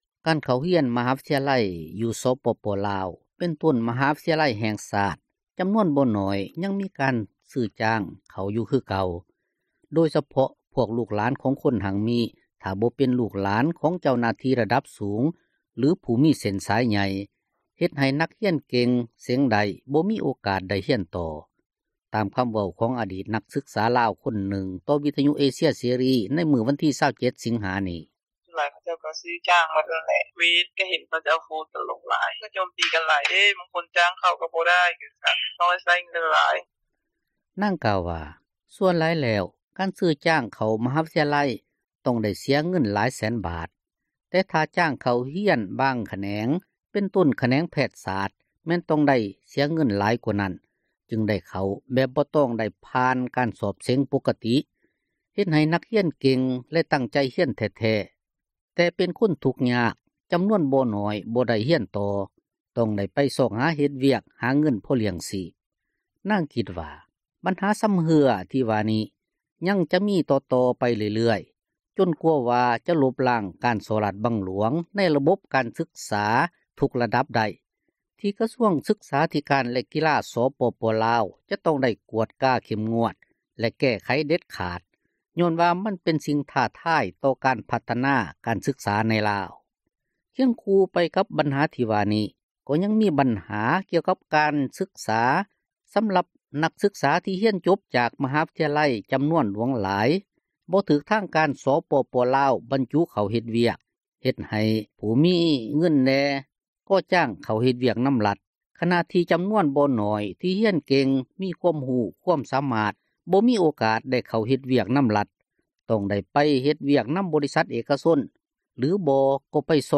ຕາມຄໍາເວົ້າຂອງອະດີດນັກສຶກສາລາວ ຄົນນຶ່ງ ຕໍ່ວິທຍຸເອເຊັຍເສຣີ ໃນມື້ວັນທີ 27 ສິງຫາ ນີ້: